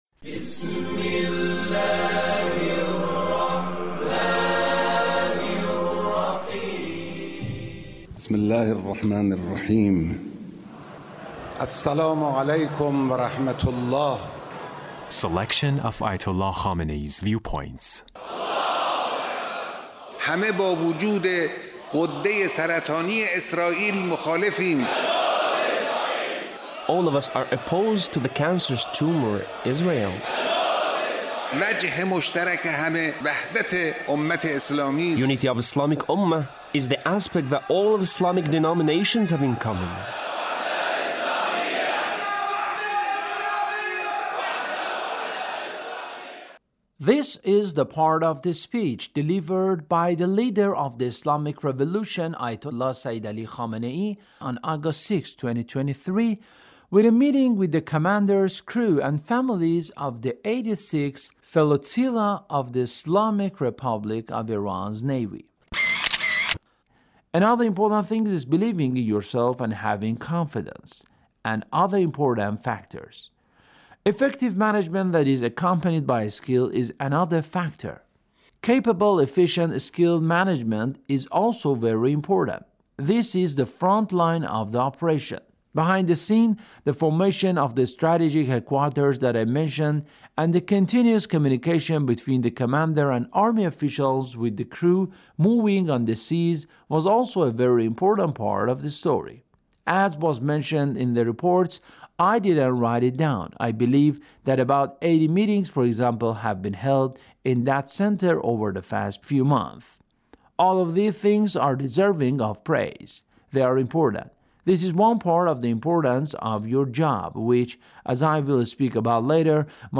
Leader's Speech in a meeting with the commanders, crew, and families of the 86th Flotilla of the Islamic Republic of Iran Navy.